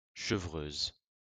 Chevreuse (French pronunciation: [ʃəvʁøz]